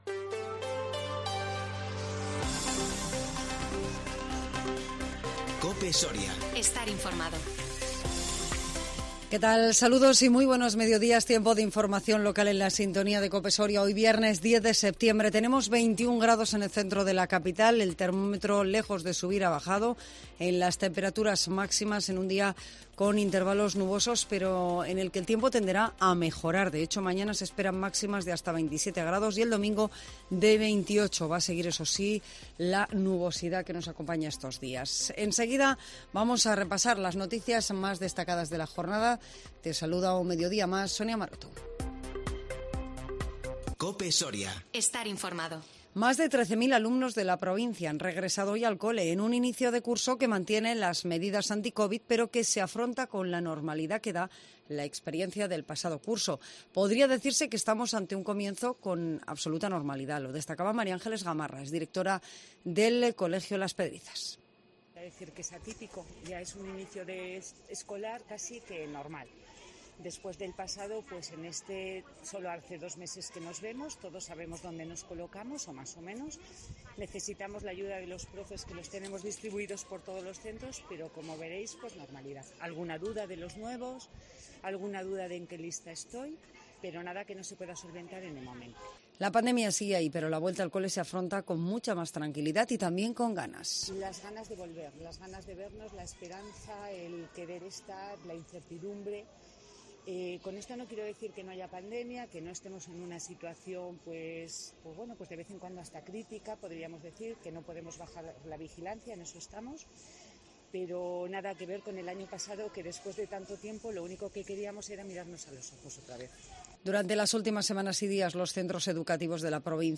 INFORMATIVO MEDIODÍA 10 SEPTIEMBRE 2021